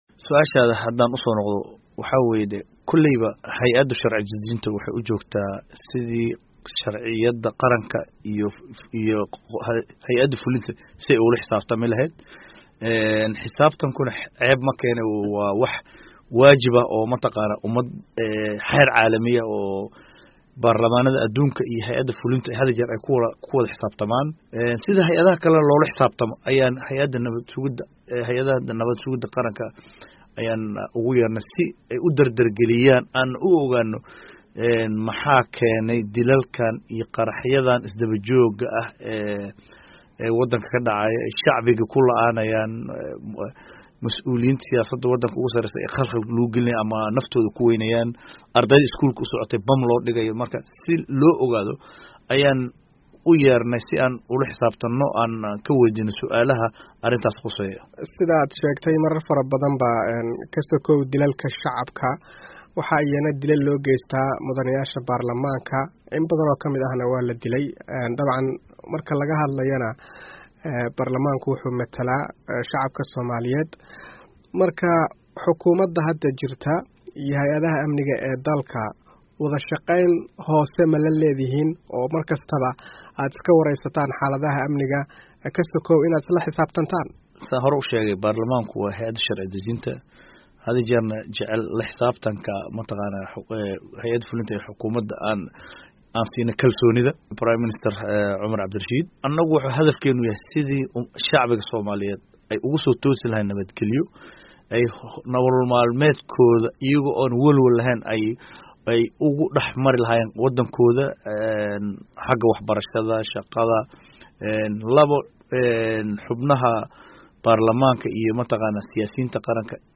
Wareysi: Xildhibaan Siciid Maxamed Xayd